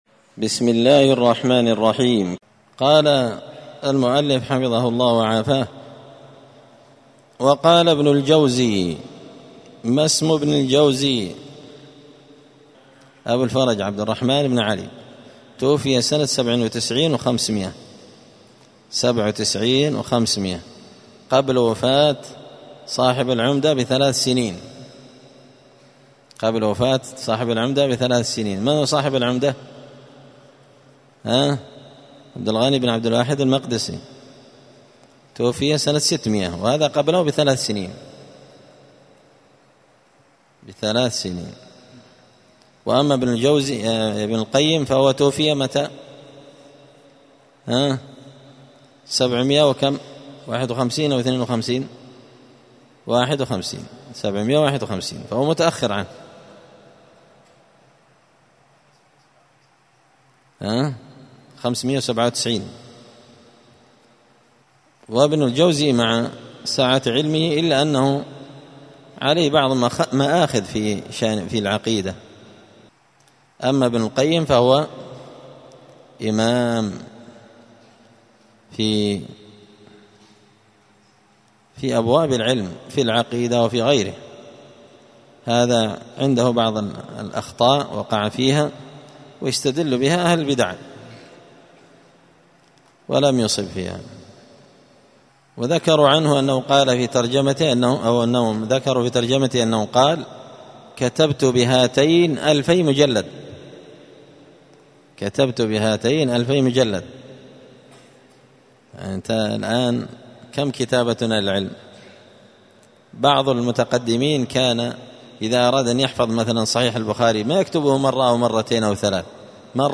دار الحديث السلفية بمسجد الفرقان
4الدرس-الرابع-من-كتاب-الفواكه-الجنية.mp3